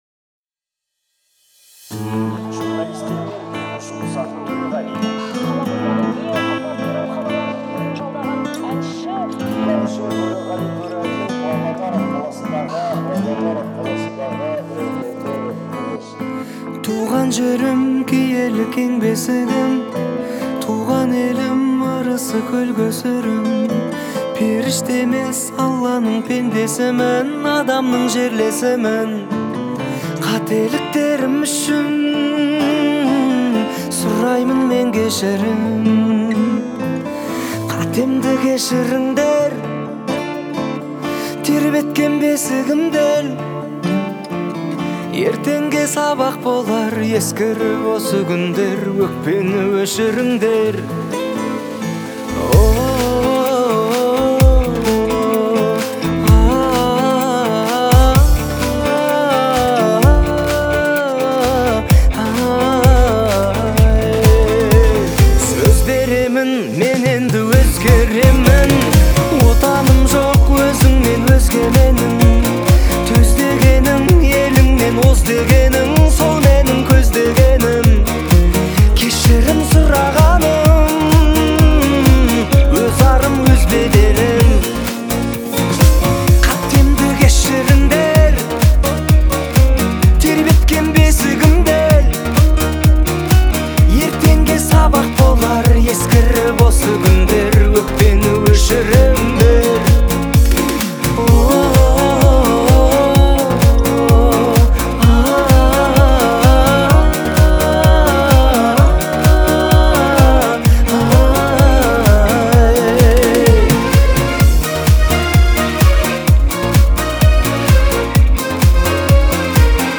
это трогательный музыкальный номер в жанре поп